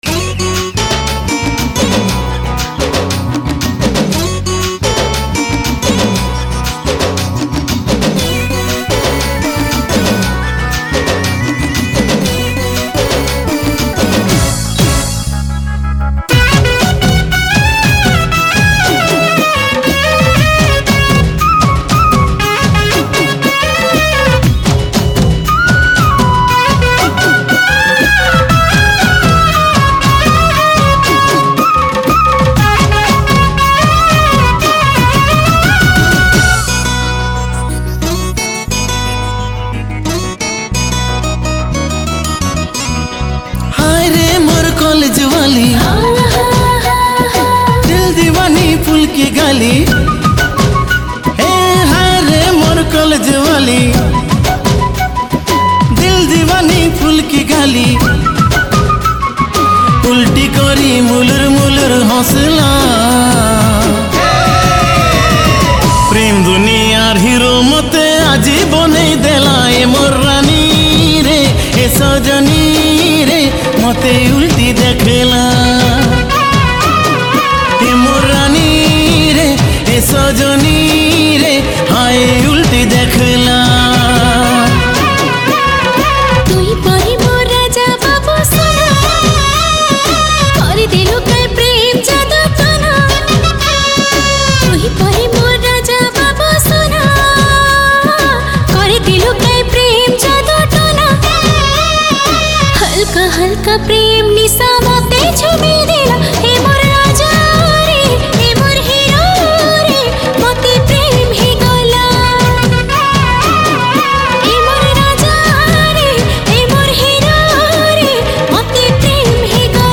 Sambapuri Single Song 2022